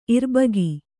♪ irbagi